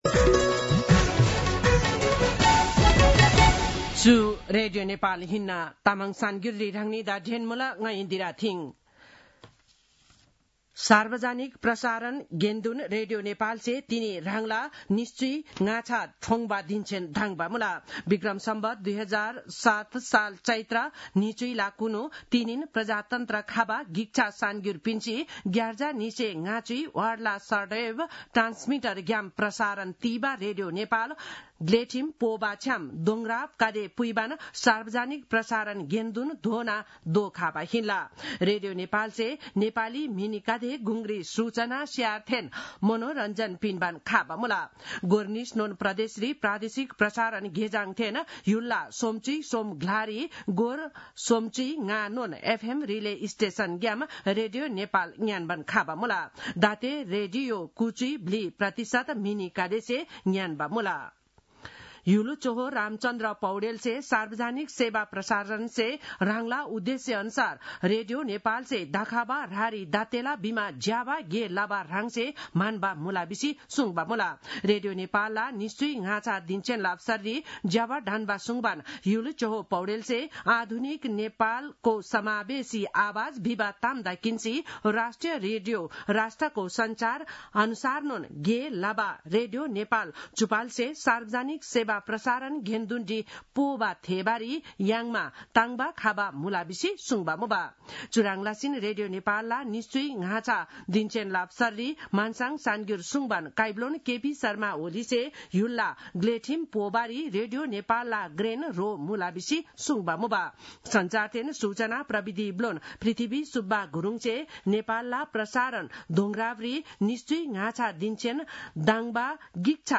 तामाङ भाषाको समाचार : २० चैत , २०८१